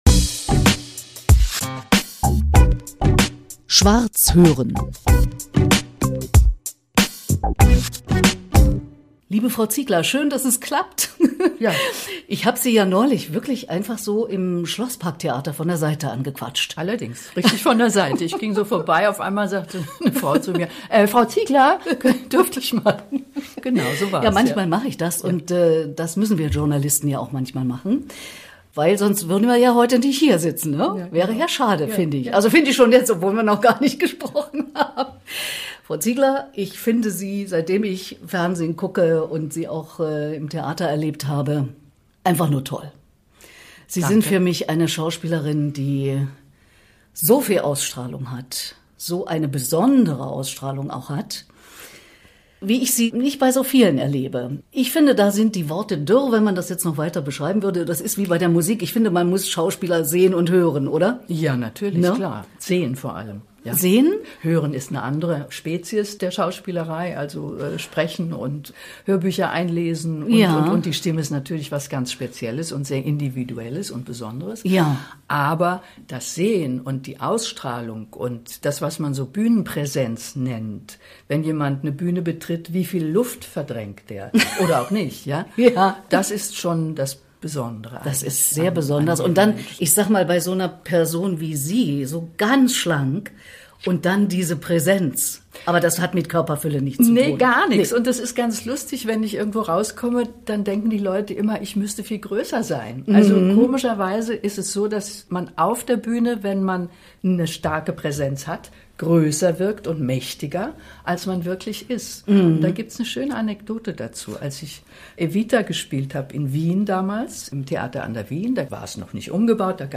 Im Hochsommer 2025 habe ich Daniela Ziegler in ihrer Wohnung in Berlin-Charlottenburg besucht und wir haben über ihr Leben gesprochen, u.a. darüber, warum sie seit mehr als 20 Jahren die "José Carreras Stiftung" unterstützt und dass Präsenz ist, wieviel Luft Jemand verdrängt, wenn er eine Bühne betritt.